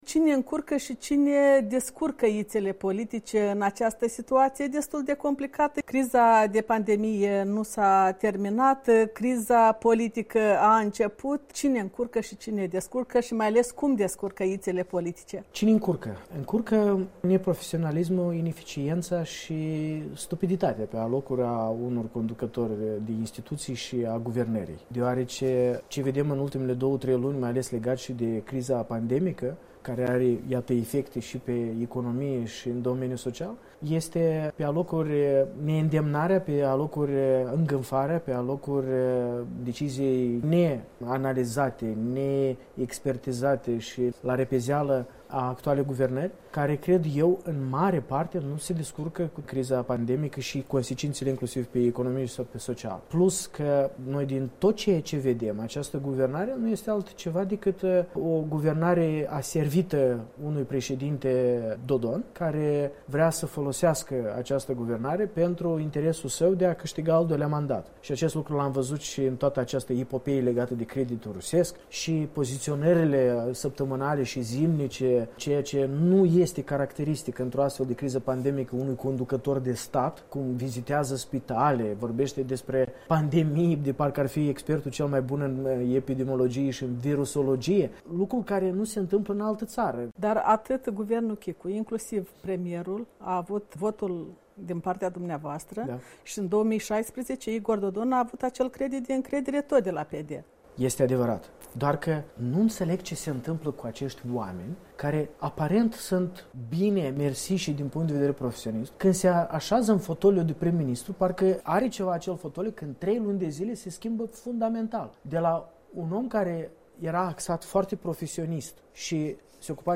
Interviu cu Andrian Candu